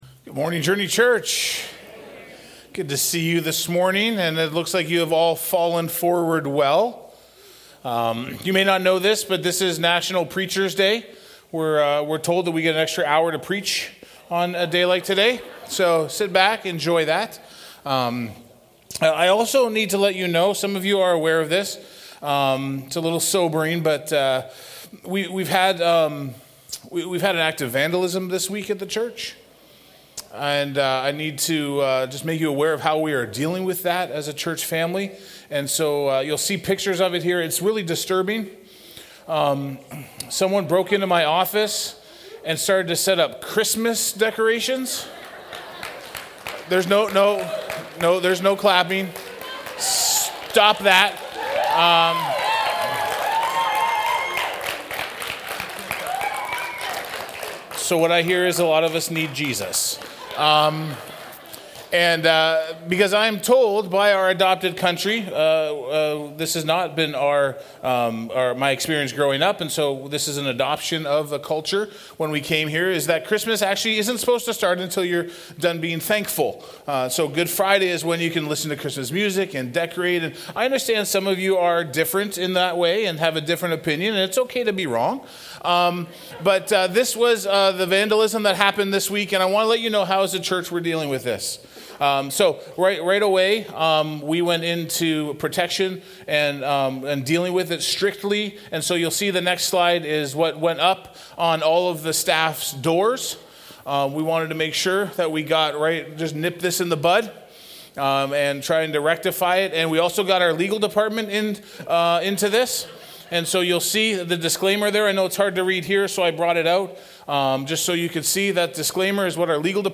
Week 1 of The Table Sermon Series. In the Christian faith, gathering around a table with others holds profound significance.